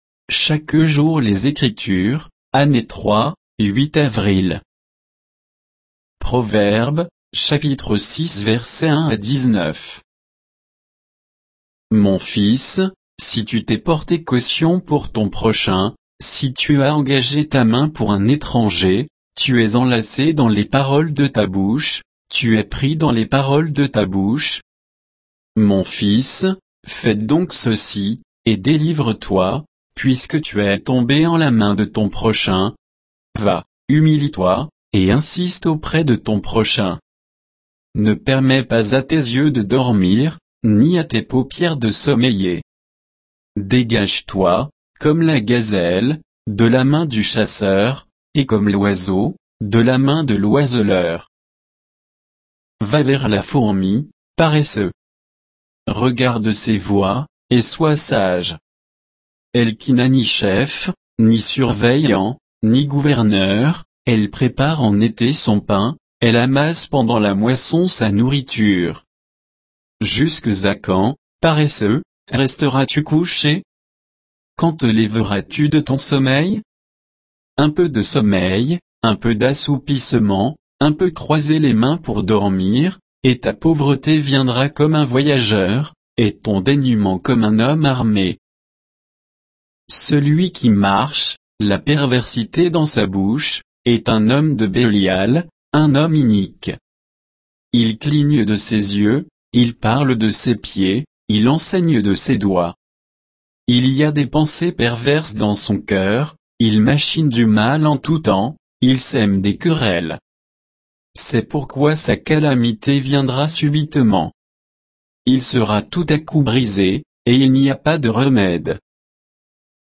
Méditation quoditienne de Chaque jour les Écritures sur Proverbes 6, 1 à 19